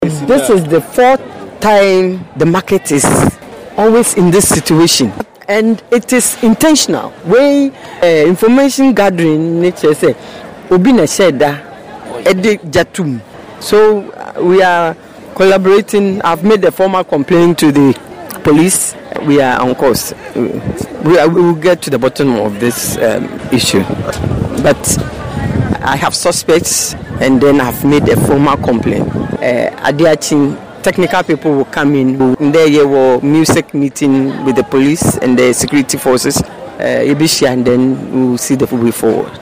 Listen to the MCE